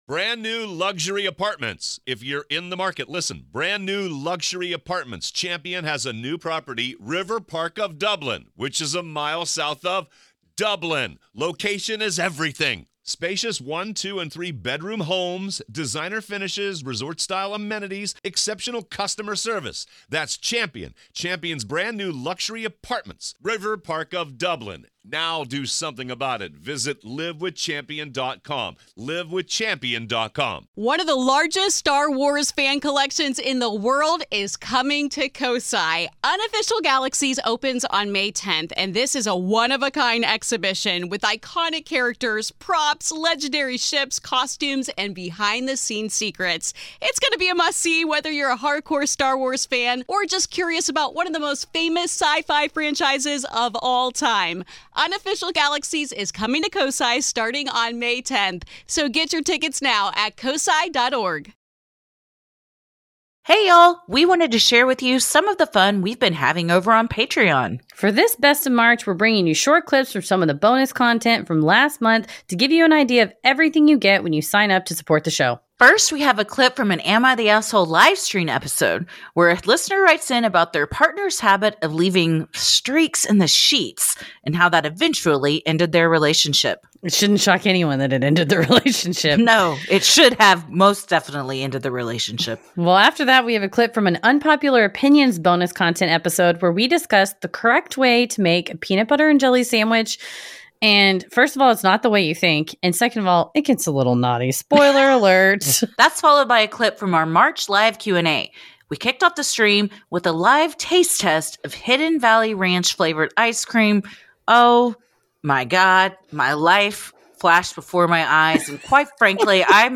This mega-mix of clips brings you some of our favorite segments from the month of March.